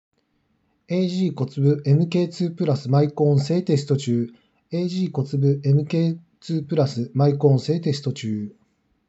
✅「ag COTSUBU MK2+」マイク性能